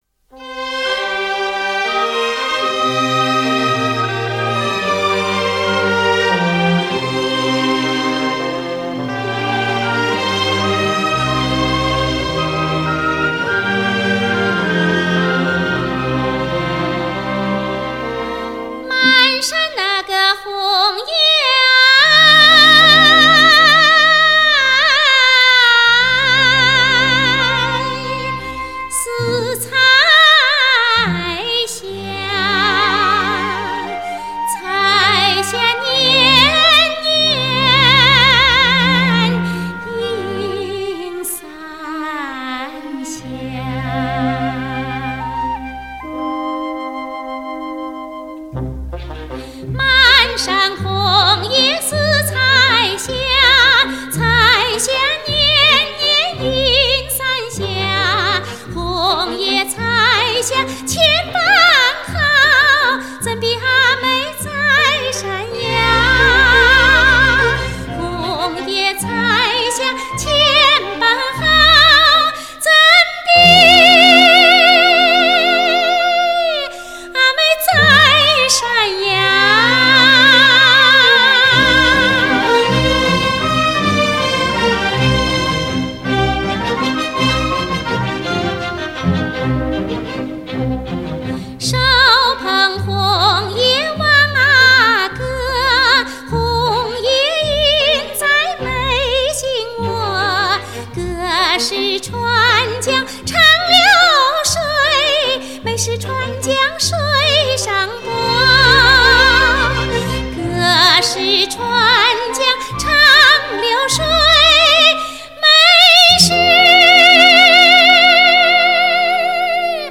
2. 现场模拟录音，1：1的直刻母带技术。